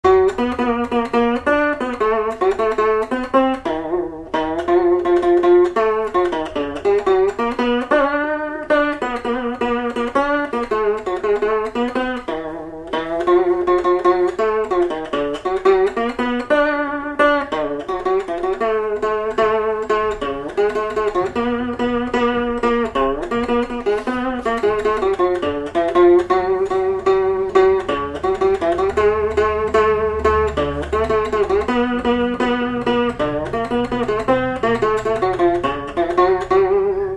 Tampon (Le)
Instrumental
danse : séga
Pièce musicale inédite